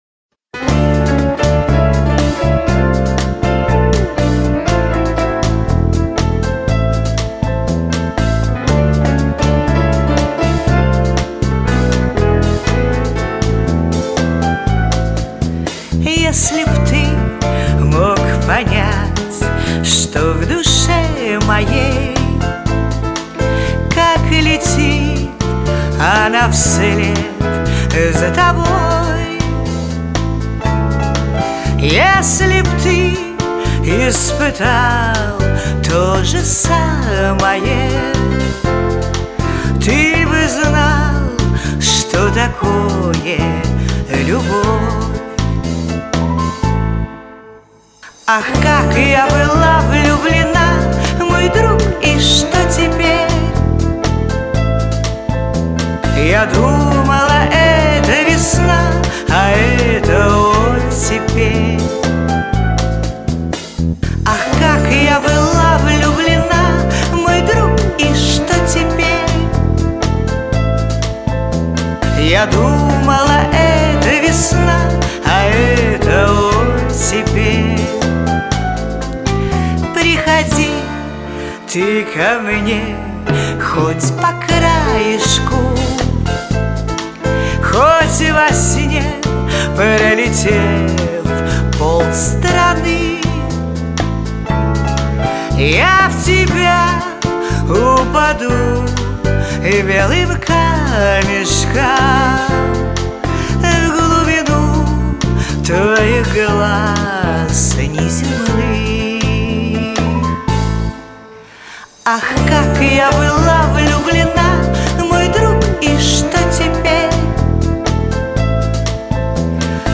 Действительно, очень необычно, что мужчина спел эту песню!)